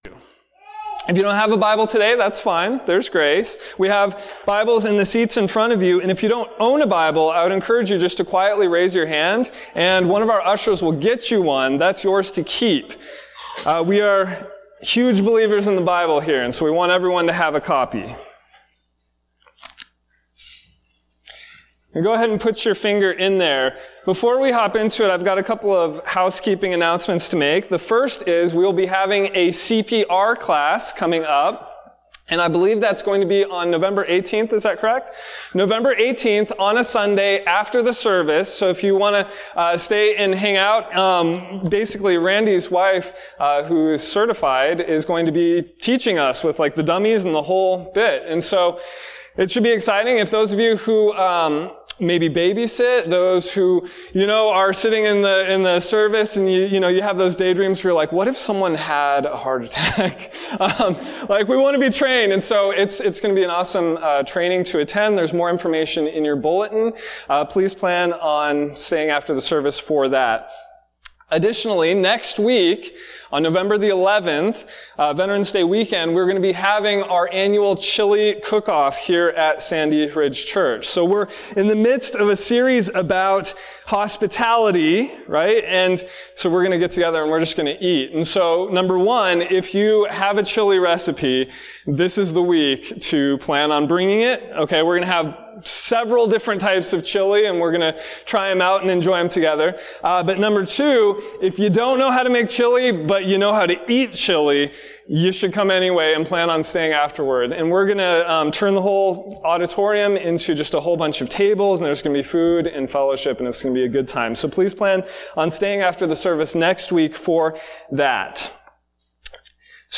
Sermons by Series | Sandy Ridge Church